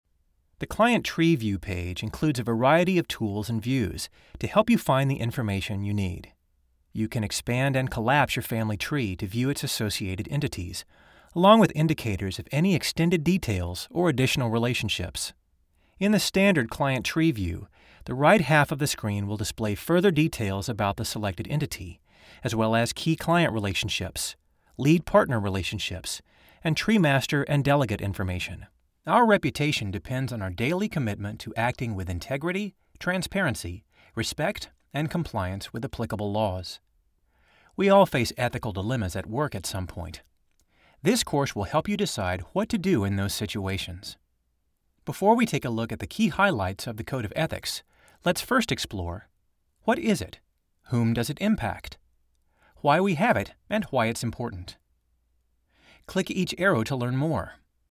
Adult
e-learning
warm
well spoken